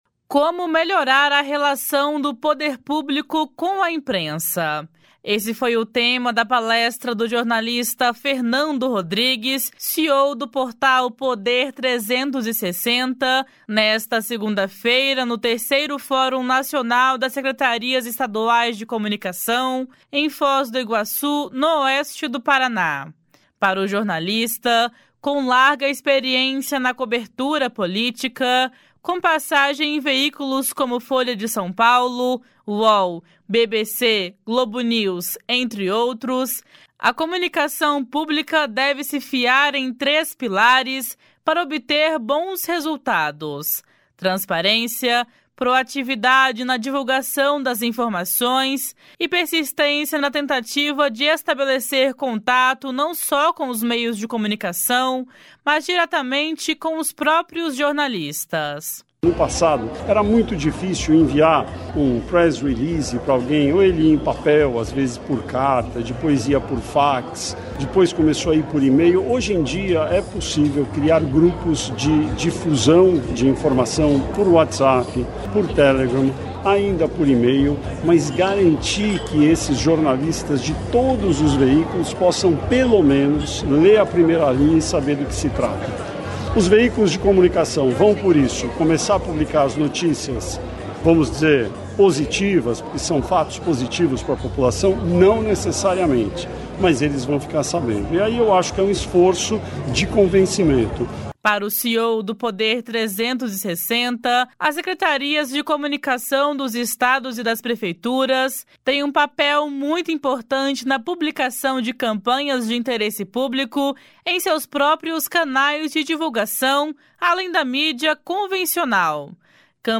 Como melhorar a relação do poder público com a imprensa. Esse foi o tema da palestra do jornalista Fernando Rodrigues, CEO do portal Poder 360°, nesta segunda-feira, no 3° Fórum Nacional das Secretarias Estaduais de Comunicação em Foz do Iguaçu, no Oeste do Paraná.
// SONORA FERNANDO RODRIGUES //